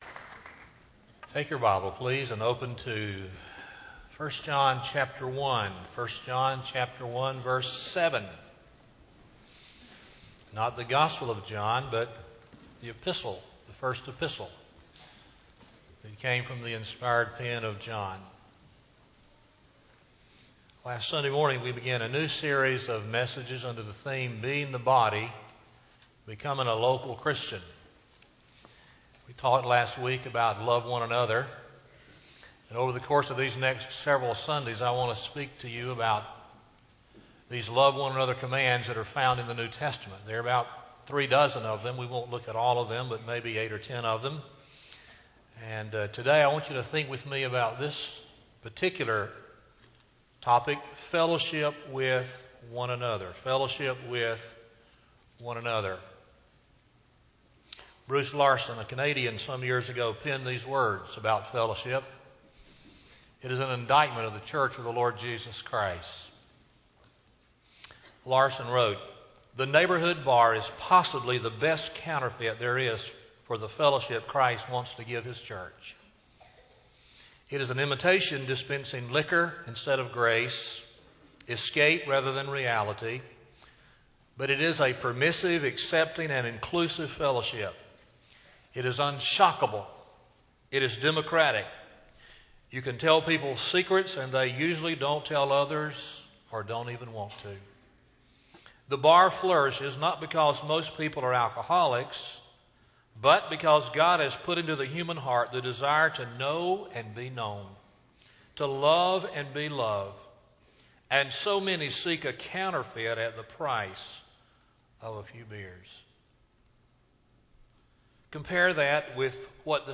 Lakeview Baptist Church - Auburn, Alabama